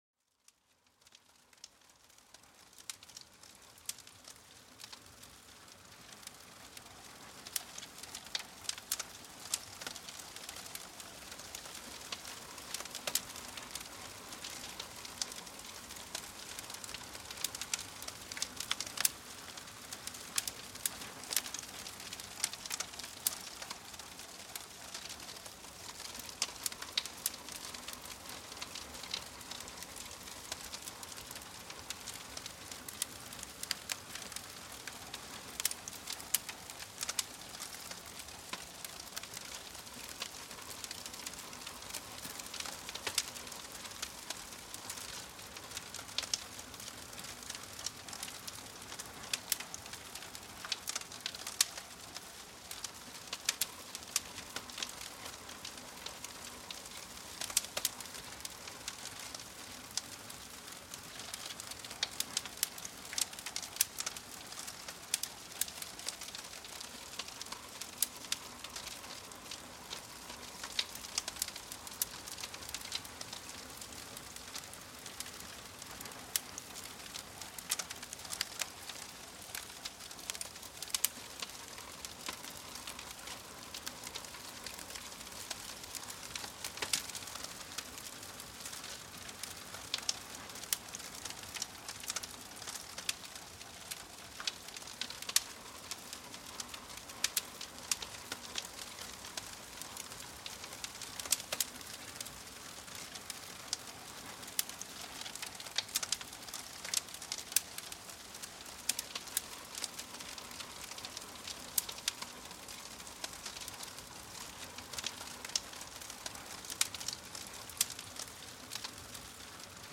Le son réconfortant du feu pour s'endormir profondément